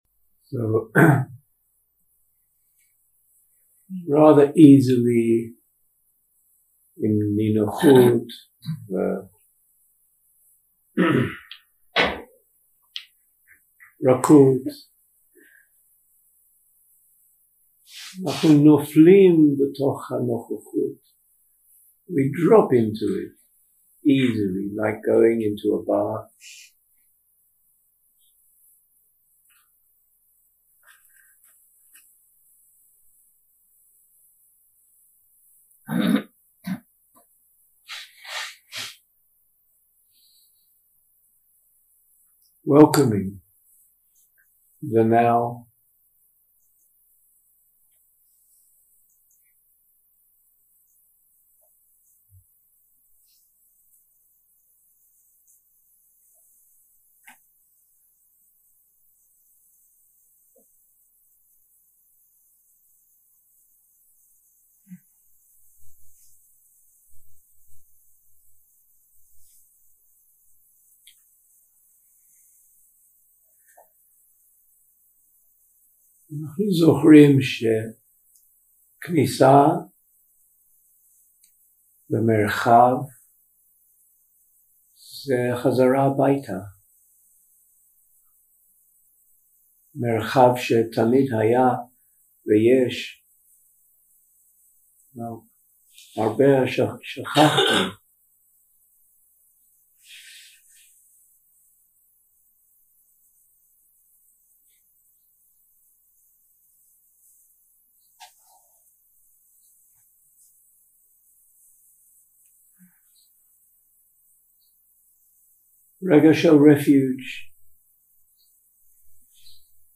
יום 5 – הקלטה 14 – צהריים – מדיטציה מונחית – חמלה ויציבות כלפי התופעות 0:00 0:00 סוג ההקלטה: Dharma type: Guided meditation שפת ההקלטה: Dharma talk language: Hebrew